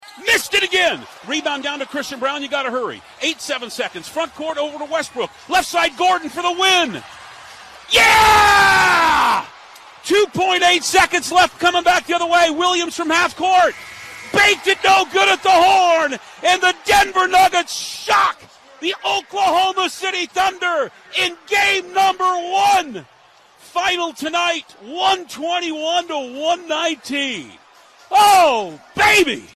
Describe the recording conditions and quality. Here is how the final call sounded on the Denver Nuggets Radio Network.